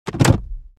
Racing, Driving, Game Menu, Ui Tab Switch Sound Effect Download | Gfx Sounds
Racing-driving-game-menu-ui-tab-switch.mp3